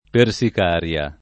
persicaria [ per S ik # r L a ]